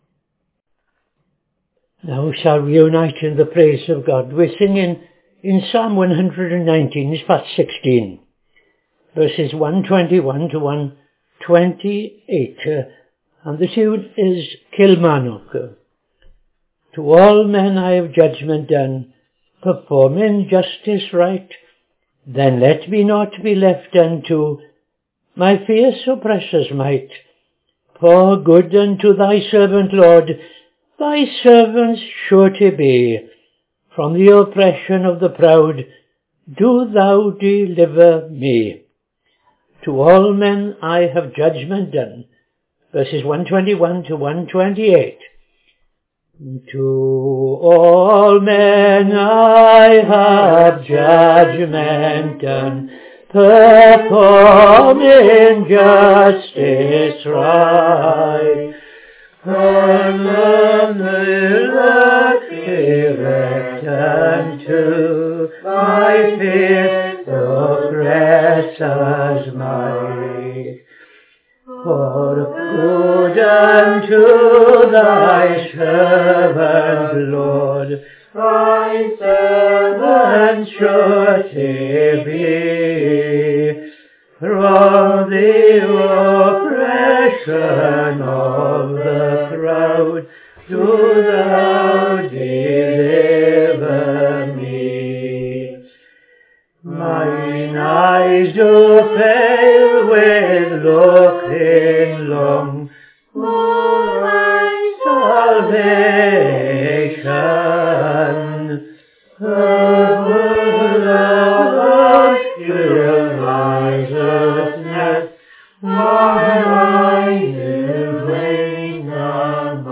Evening Service - TFCChurch
5.00 pm Evening Service Opening Prayer and O.T. Reading I Chronicles 24:1-31